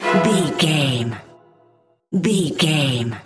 Aeolian/Minor
Fast
scary
ominous
dark
suspense
eerie